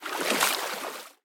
alien-biomes / sound / walking / water-03.ogg
water-03.ogg